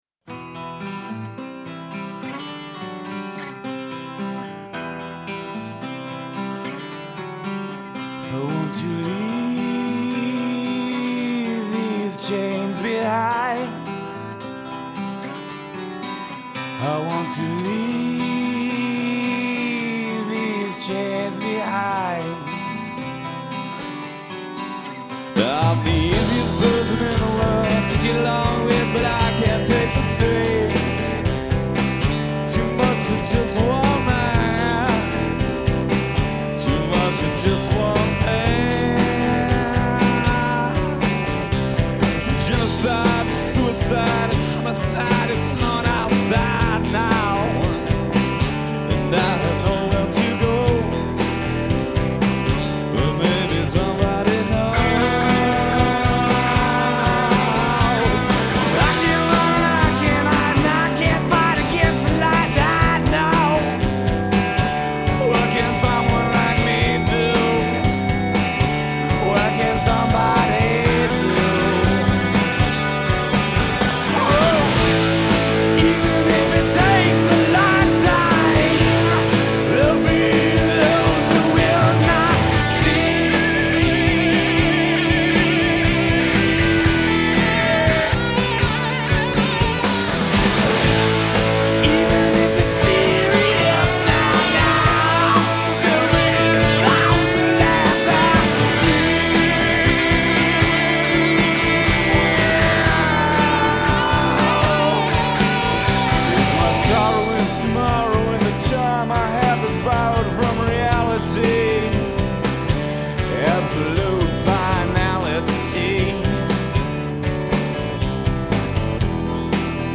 LIVE IN 09!